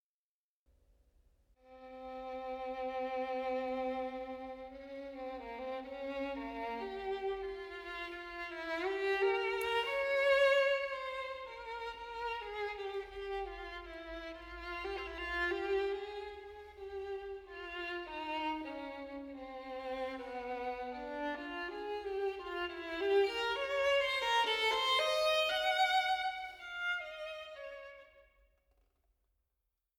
Hammerklavier